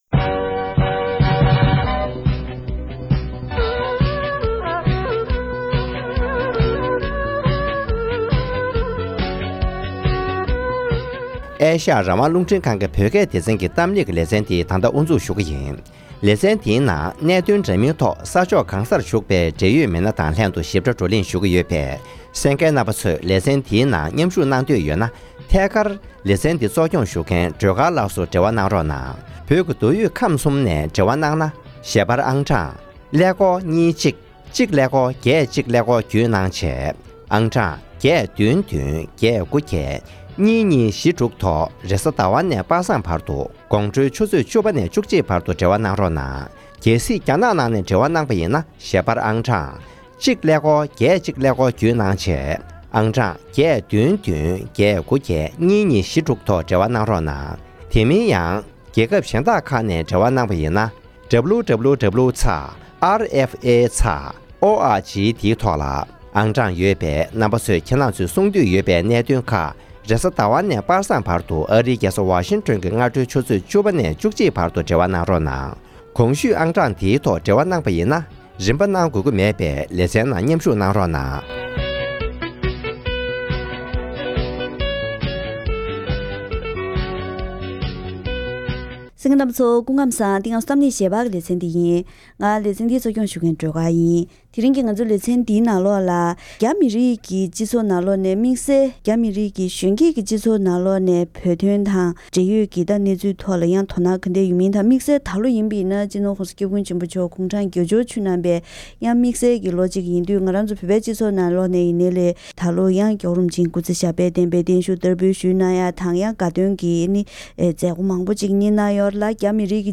༄༅། །དེ་རིང་གི་གཏམ་གླེང་ཞལ་པར་ལེ་ཚན་ནང་རྒྱ་མི་རིགས་ཀྱི་གཞོན་སྐྱེས་ཁྲོད་ནས་བོད་དོན་ཐོག་དོ་སྣང་བྱེད་མཁན་མང་རུ་འགྲོ་བཞིན་ཡོད་པ་དང་དམིགས་བསལ་འདི་ལོ་༧གོང་ས་མཆོག་གི་སྐུའི་གྱ་སྟོན་ལ་འཛམ་གླིང་ས་ཕྱོགས་གང་སར་ཡོད་པའི་རྒྱ་མི་མང་པོས་འཚམས་འདྲི་ཞུས་ཡོད་པའི་སྐོར་ངོ་སྤྲོད་ཞུས་པ་ཞིག་གསན་རོགས་གནང་།